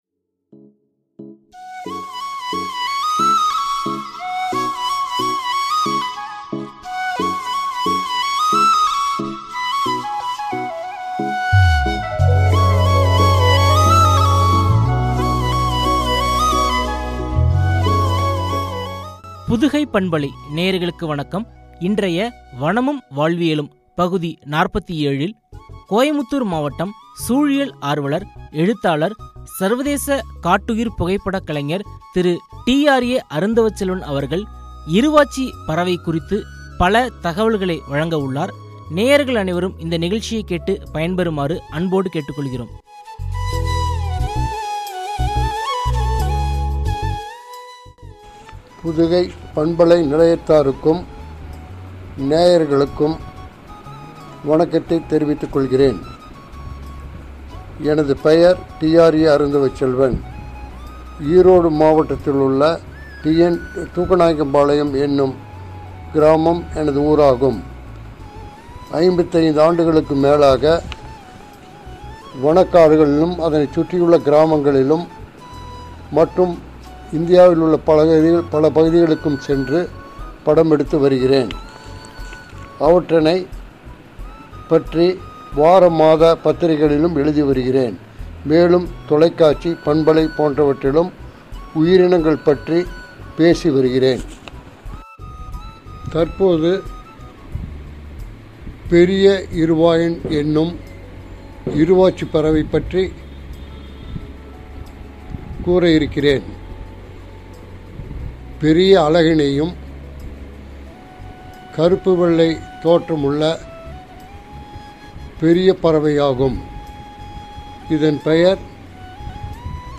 “இருவாச்சி பறவை” குறித்து வழங்கிய உரையாடல்.